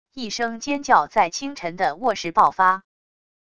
一声尖叫在清晨的卧室暴发wav音频